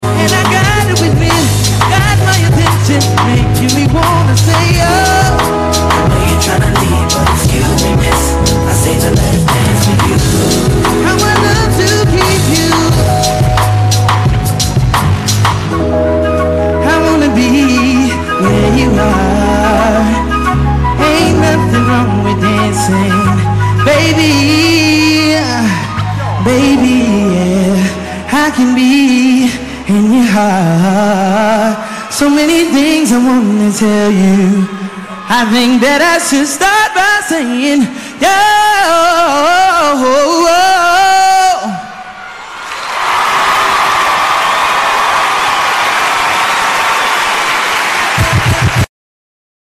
BET Awards 2006 PART 3 .